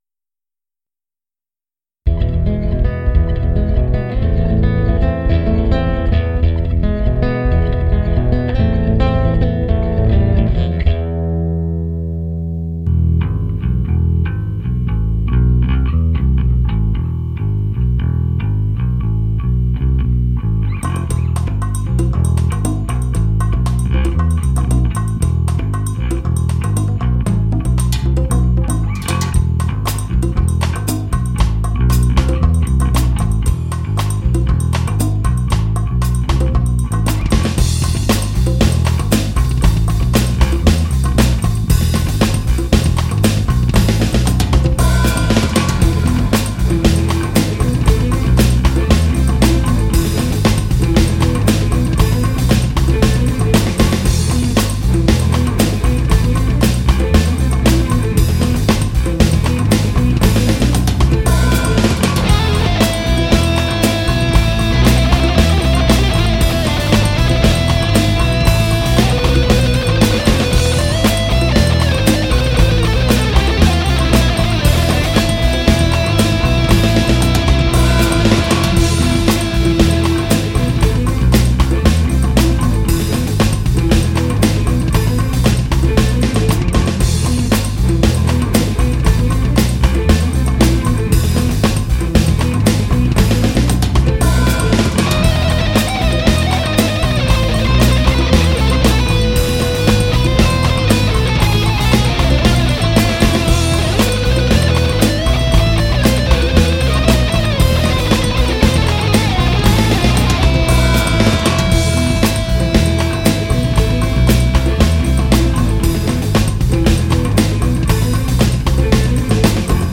through a Marshall stack.